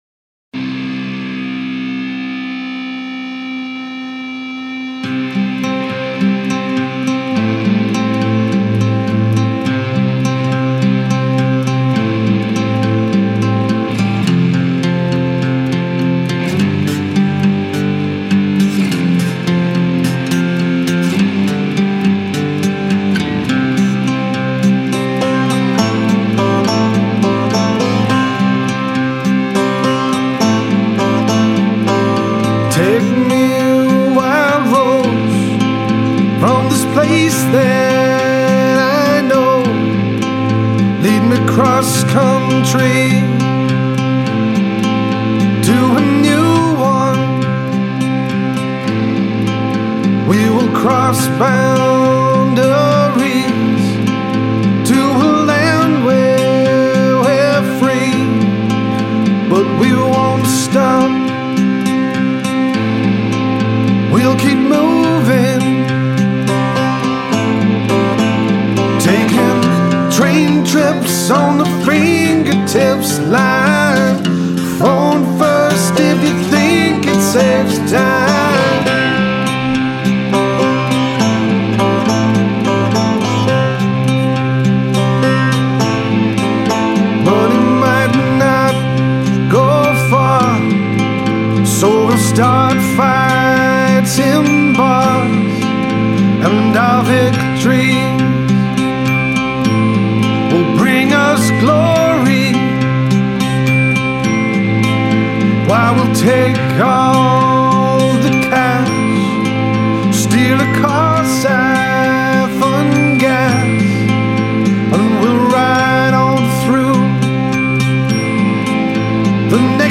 cranky, confrontational and darkly alluring.